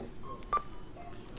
consumption » carrefour cashdesk 191210
描述：19.12.2010: 20.50. Cash desk in the Carrrefour supermarket. I am buying cocacola. the supermarket was almost empty.
标签： cashdesk cashier fieldrecording phonering supermarket voice
声道立体声